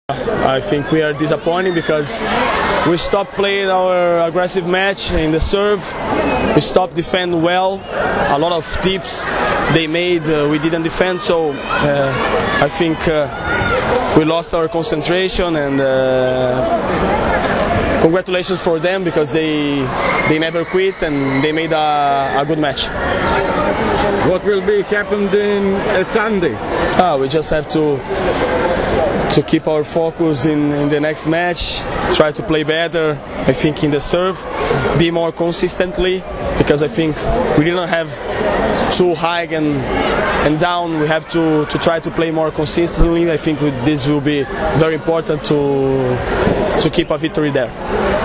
IZJAVA BRUNA REZENDEA